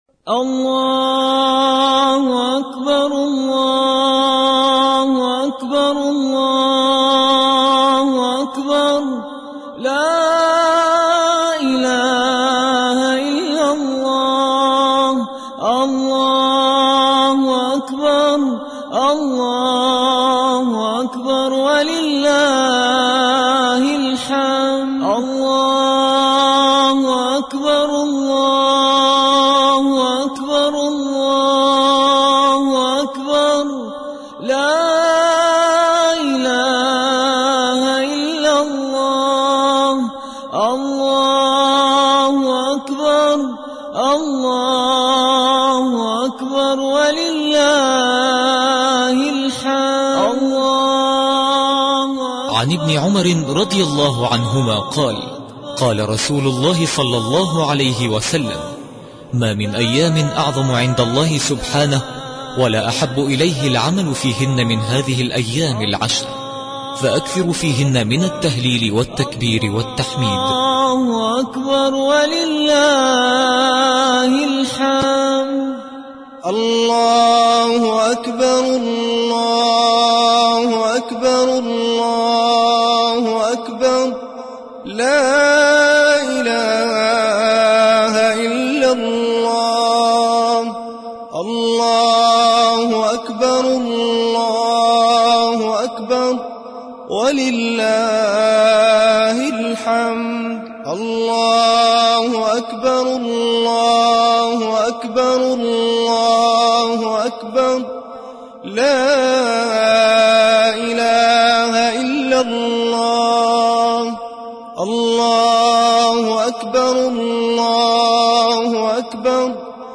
تكبيرات العيد والعشر - المكتبة الإسلامية
347_TakbeertAl3eed.mp3